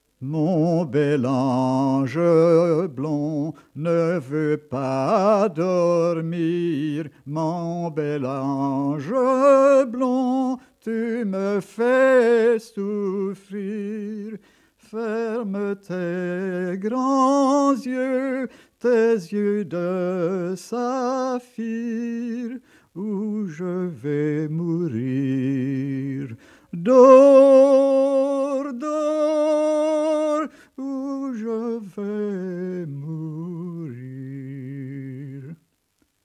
Genre : chant
Type : berceuse
Interprète(s) : Anonyme (homme)
Incipit : E D C E D E D C G F